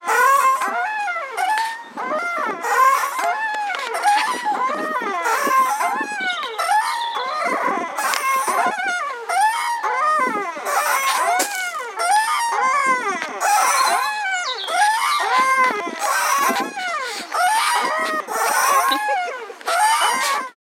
Creaking swing, Nantes